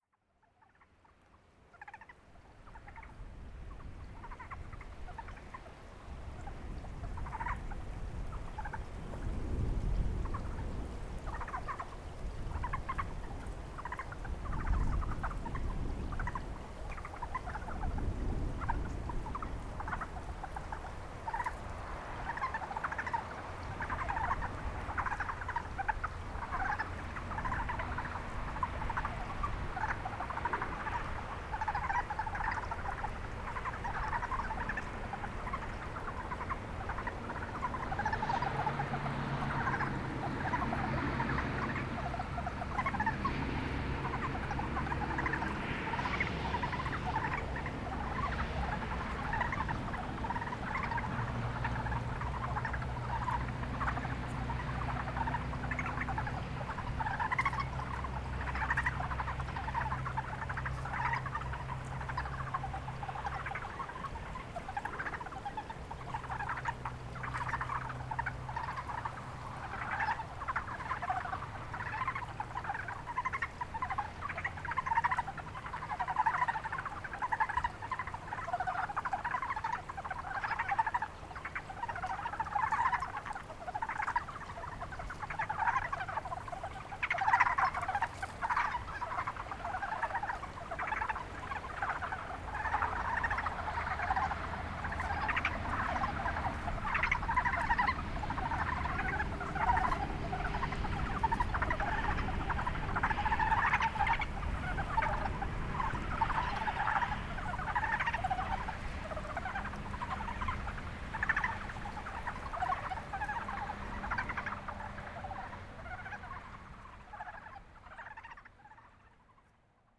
ヤマアカガエル
ZOOM H6, RODE NT5 ORTF STEREO2015年3月17日 鶴岡市
３月半ばだから順当だろうか・・・昼間に鳴いている集団があった。人の気配には敏感で、じっと息を殺して待っていてもなかなか鳴いてくれない。